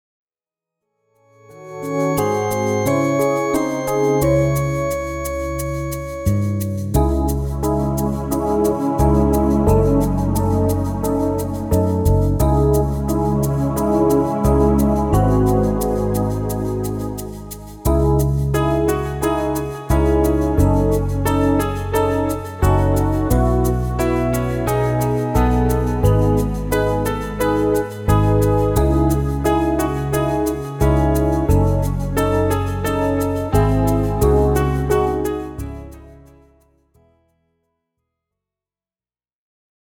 - Instrumental (+0.99 EUR)